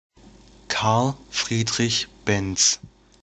Carl (or Karl) Friedrich Benz (German: [kaʁl ˈfʁiːdʁɪç ˈbɛnts]